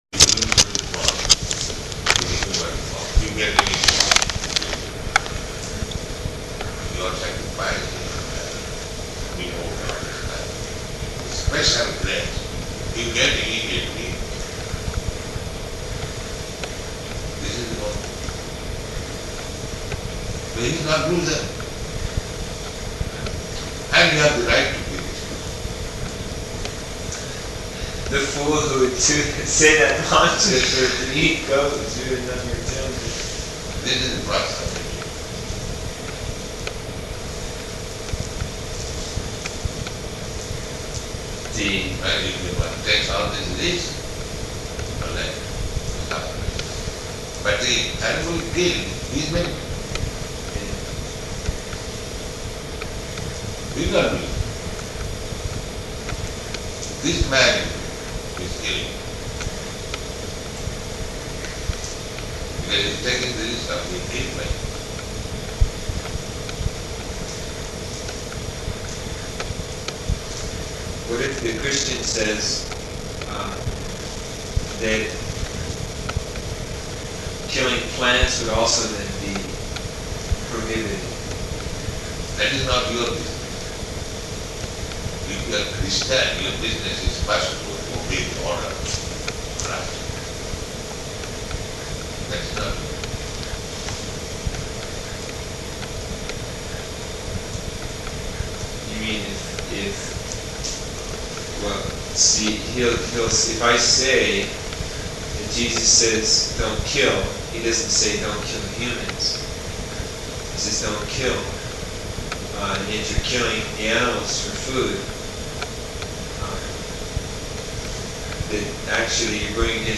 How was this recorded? Location: Honolulu [audio very indistinct throughout]